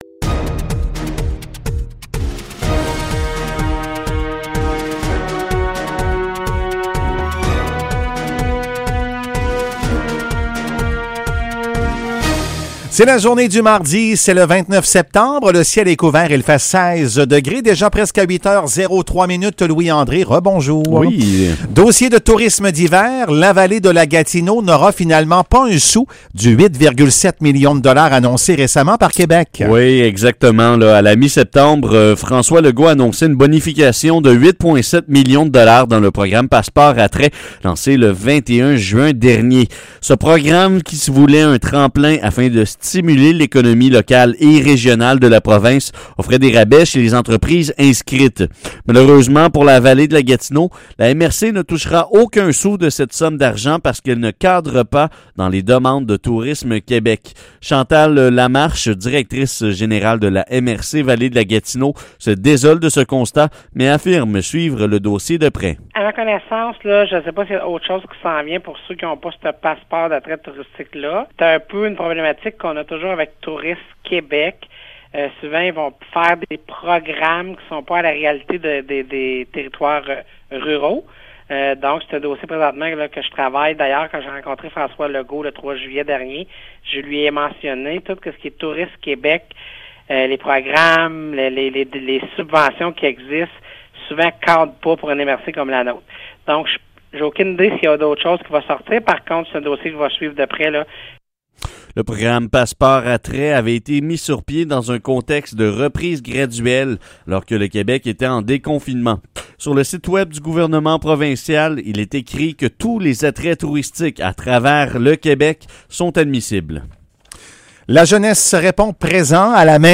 Nouvelles locales - 29 septembre 2020 - 8 h